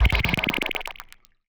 TM88 StarWarsFX.wav